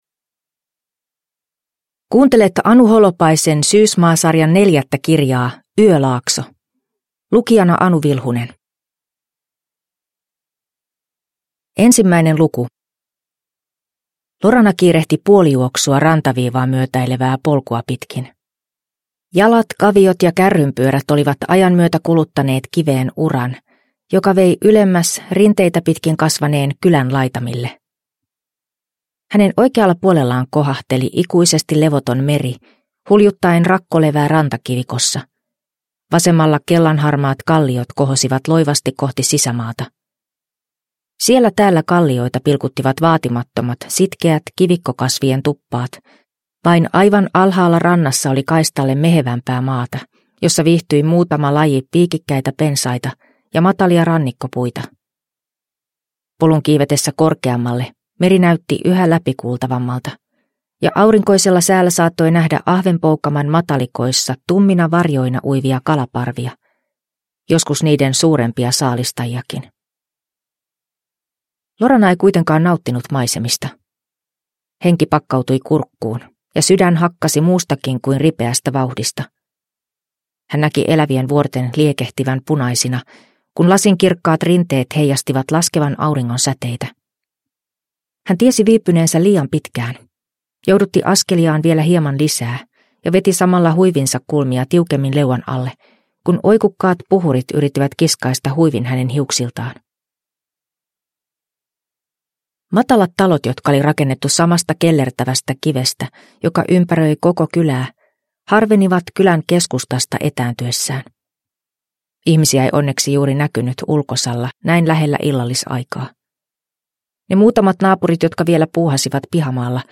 Yölaakso – Ljudbok